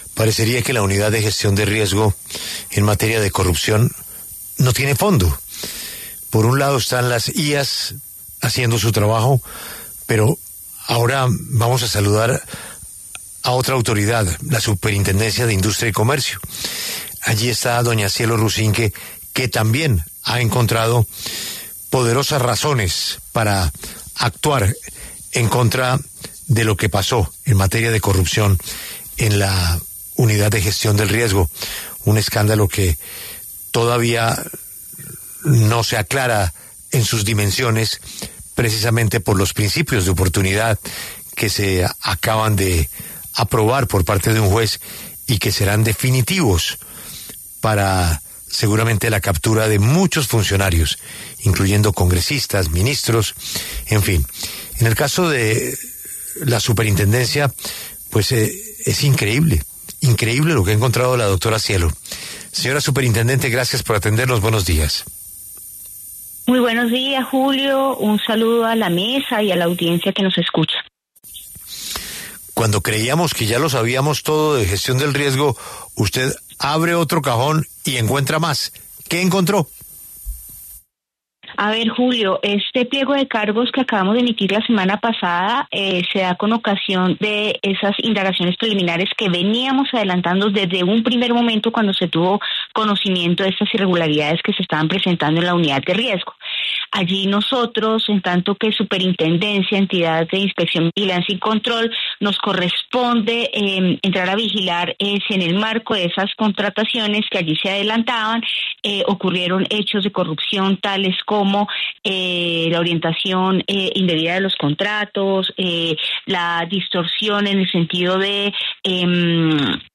En diálogo con La W, Cielo Rusinque, superintendente de Industria y Comercio, aseguró que fueron seis los contratos con los que se habrían favorecido a cinco empresas dentro de la Unidad Nacional de Gestión de Riesgo de Desastres (UNGRD), por lo que la autoridad formuló pliego de cargos contra 13 personas, entre los que están exfuncionarios, excontratistas y personas allegadas a estas empresas.